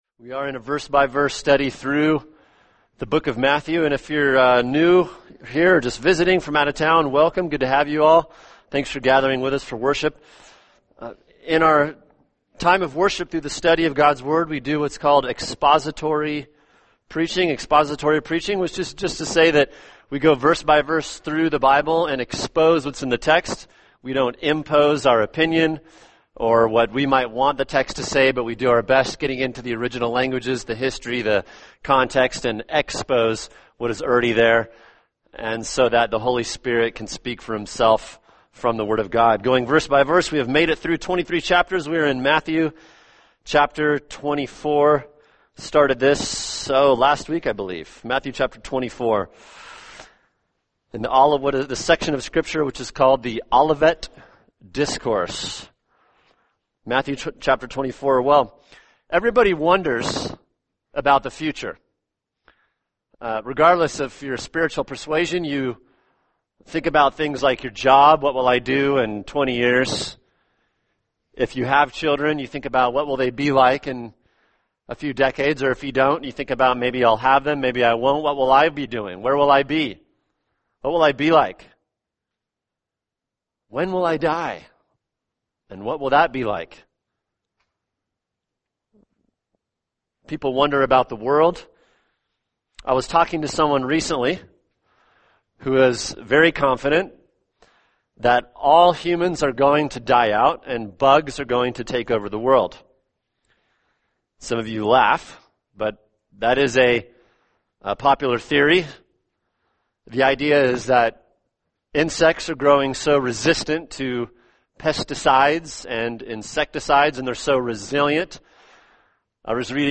[sermon] Matthew 24:9-14 The End Times: The Beginning of the Tribulation | Cornerstone Church - Jackson Hole